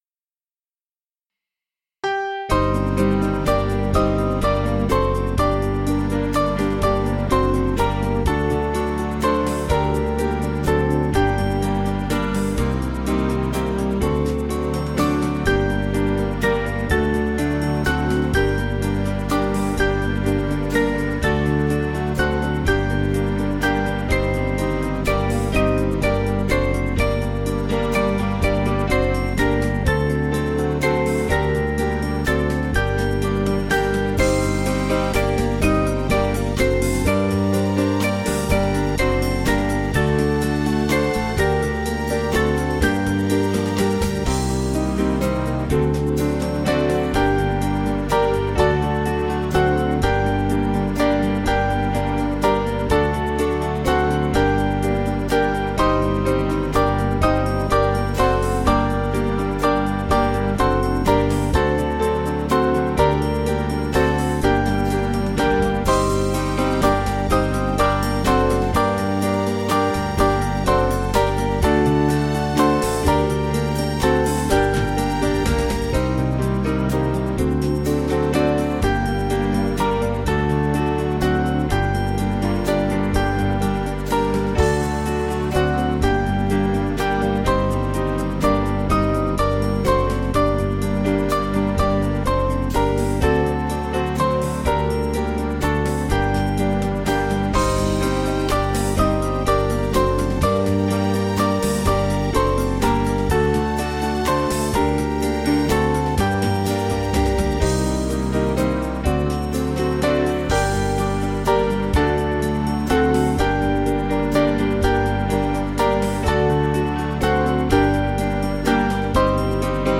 Small Band
(CM)   6/Gm 477.5kb